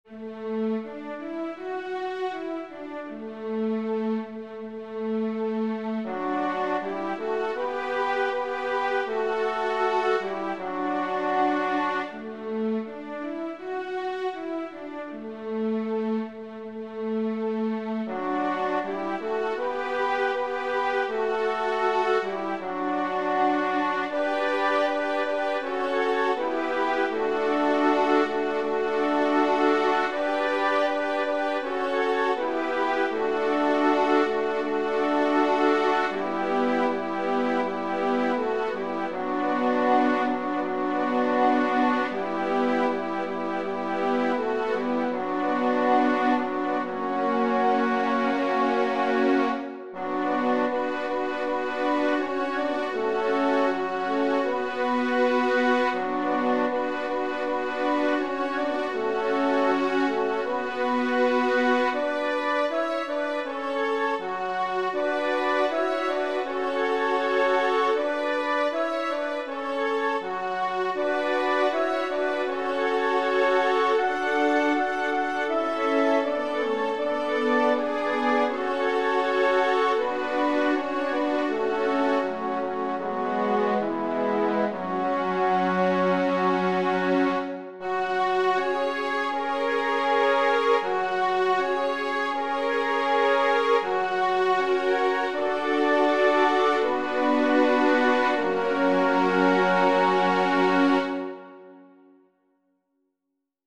Number of voices: 3vv Voicing: SSA Genre: Sacred
Language: Latin Instruments: A cappella